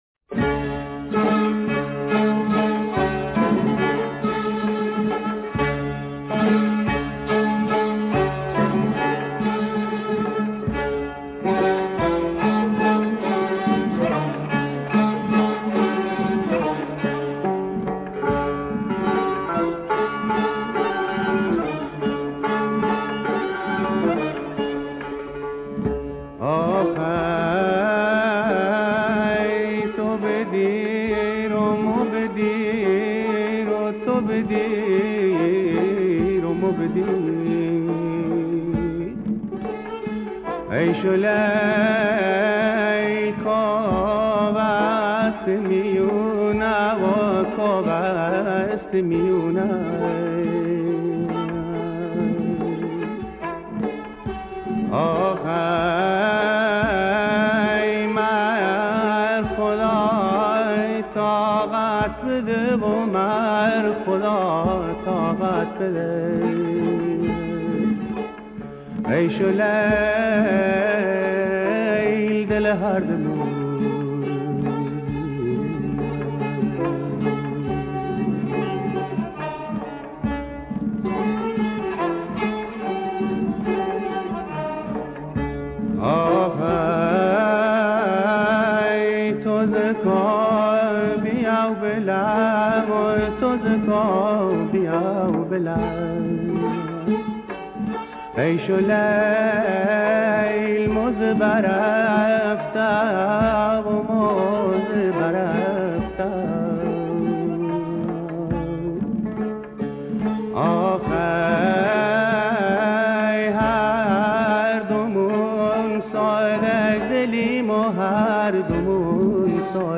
موسیقی محلی بختیاری